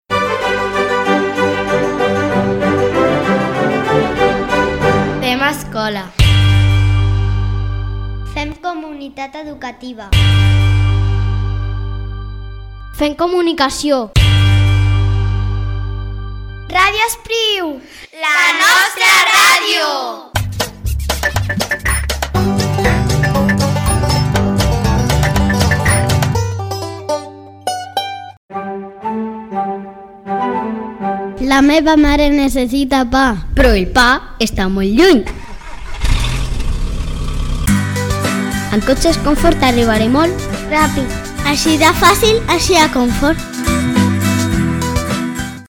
Indicatiu de l'emissora i publicitat de cotxes Comfort.